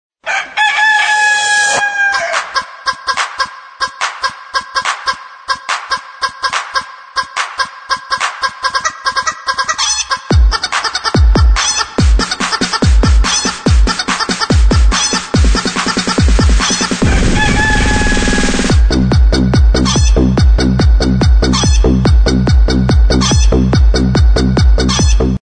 Rooster